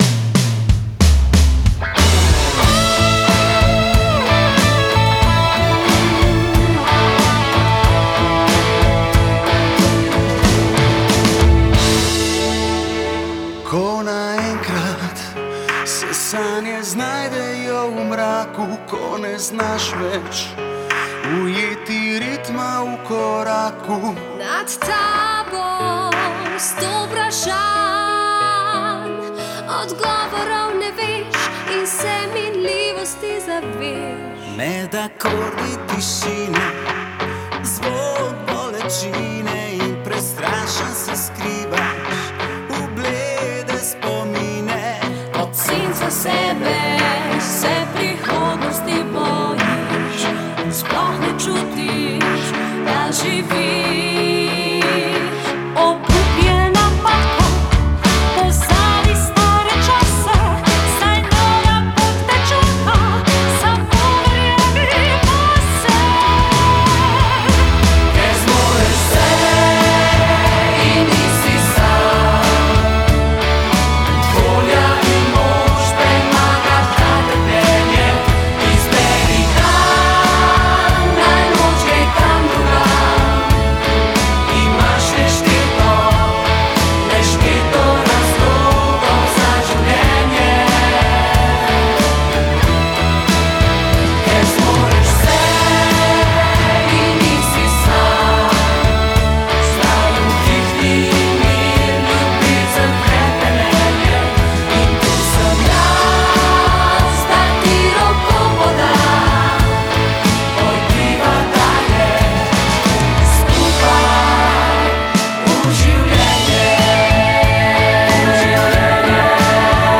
POSNETA HIMNA DRUŠTVA ONKOLOŠKIH BOLNIKOV
Skladbo so v studiu Aboks v Trzinu posneli